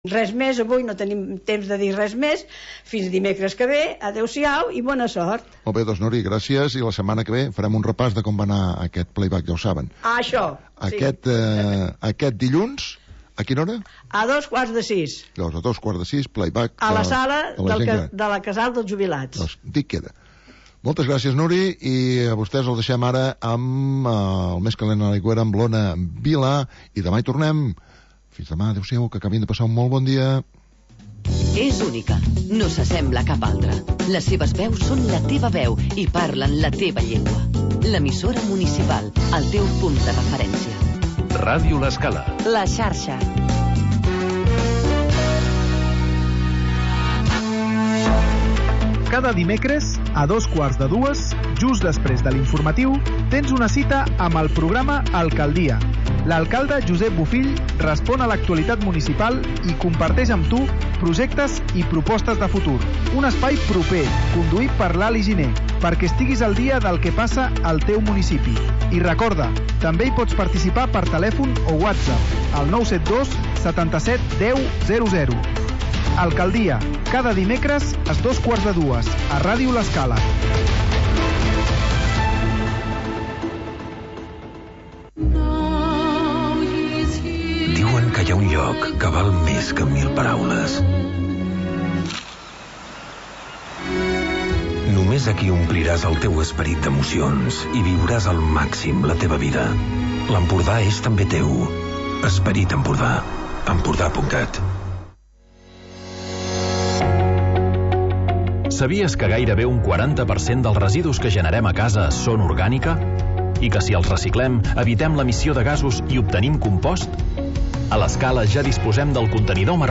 Magazin d'entretiment per acompanyar el migdiaompanyar